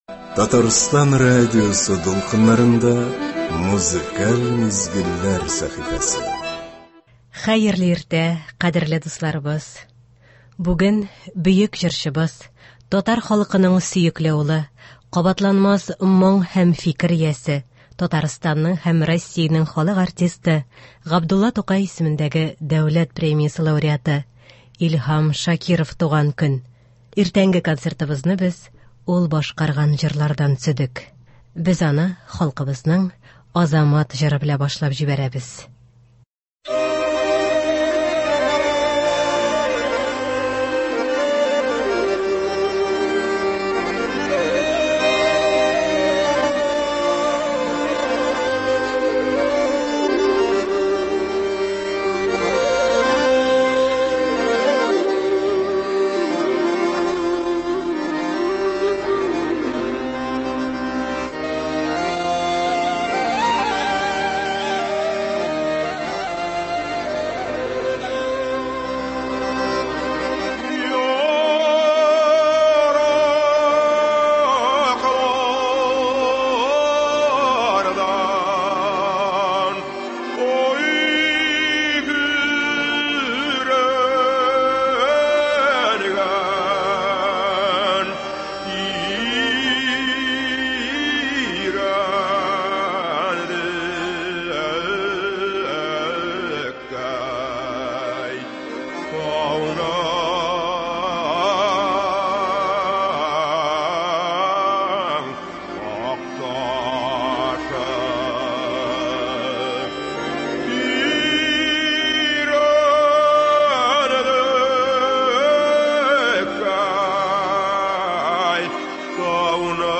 Иртәнге концертыбызны без ул башкарган җырлардан төзедек.